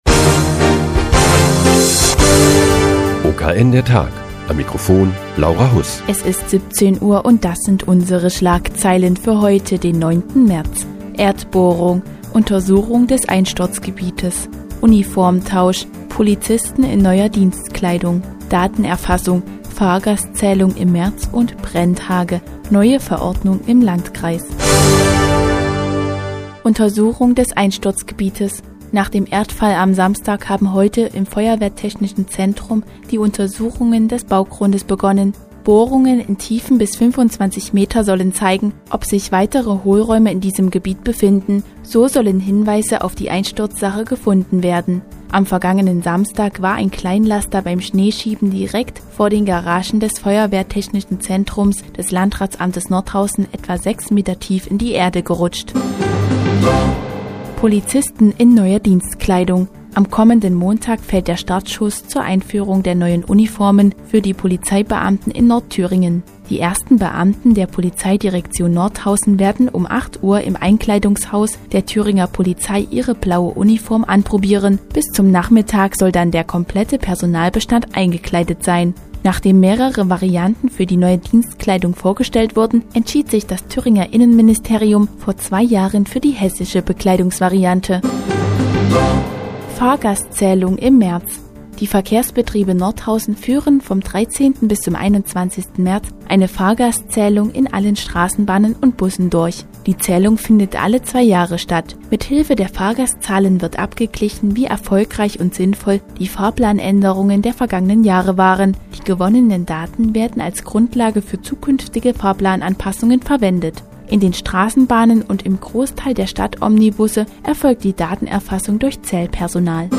Die tägliche Nachrichtensendung des OKN ist nun auch in der nnz zu hören. Heute geht es um die neue Dienstkleidung der Polizeibeamten in Nordthüringen und eine Fahrgastzählung der Verkehrsbetriebe Nordhausen.